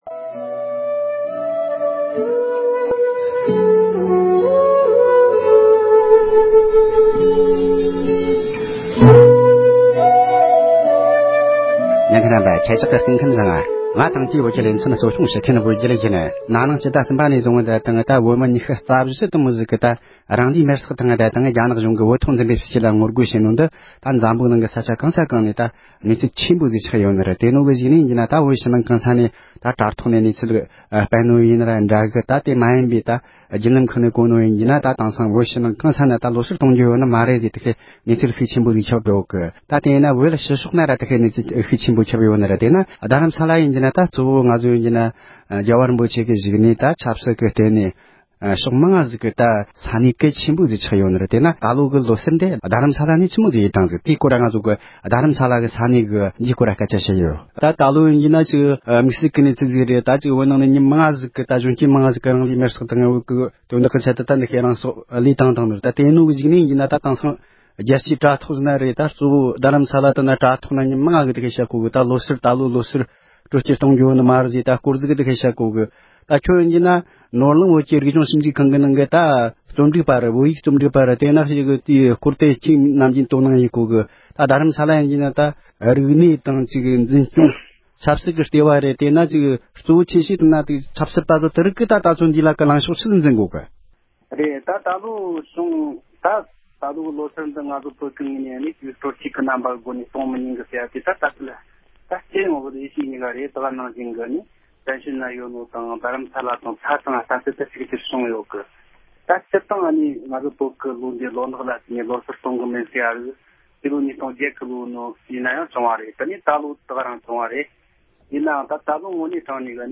བཞུགས་སྒར་རྡ་རམ་ས་ལར་ལོ་གསར་གྱི་རྣམ་པ་ཡོད་མེད་ཐད་འབྲེལ་ཡོད་མི་སྣ་དང་གླེང་མོལ་ཞུས་པ།
སྒྲ་ལྡན་གསར་འགྱུར།